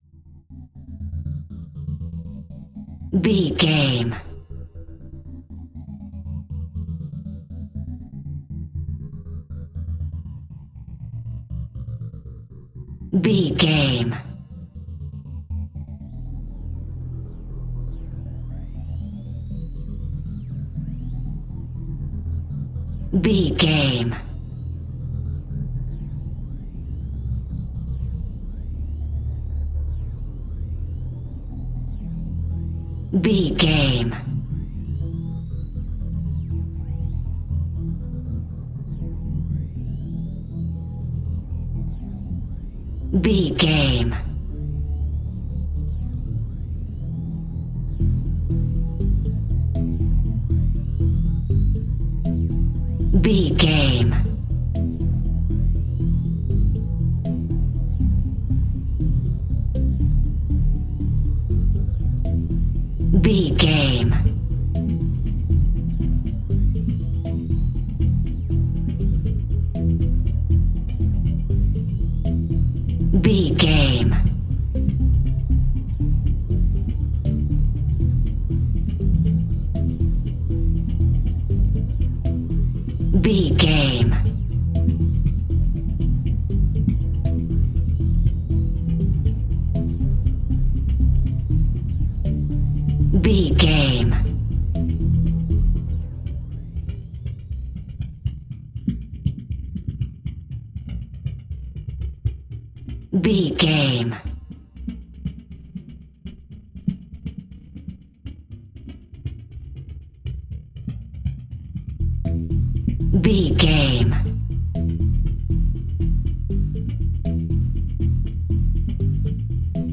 Tension Music Bed for Reality TV.
Thriller
Aeolian/Minor
scary
ominous
dark
suspense
haunting
eerie
synthesiser
percussion